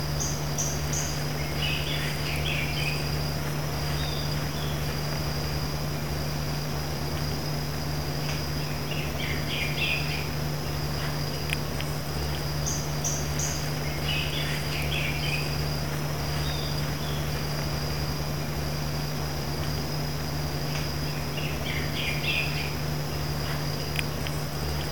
Red-crowned Ant Tanager (Habia rubica)
Location or protected area: Parque Nacional Iguazú
Condition: Wild
Certainty: Recorded vocal